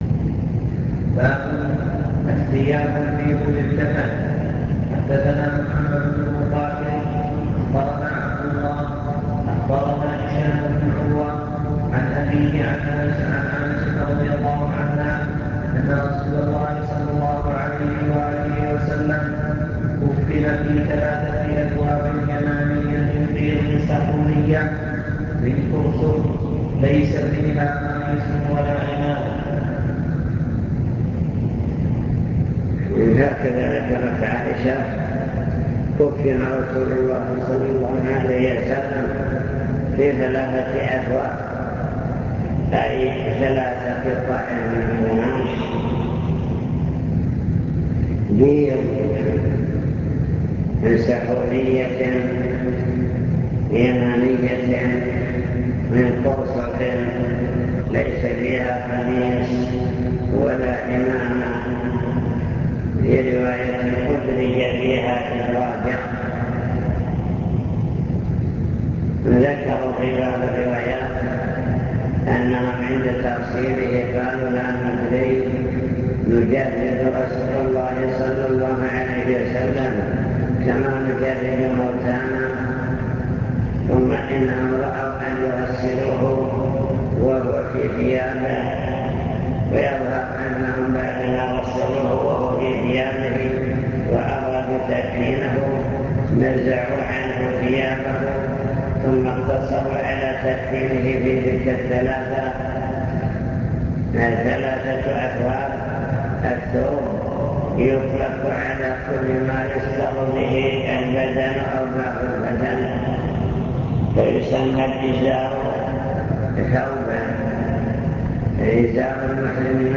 المكتبة الصوتية  تسجيلات - محاضرات ودروس  محاضرة في الزلفى مع شرح لأبواب من كتاب الجنائز في صحيح البخاري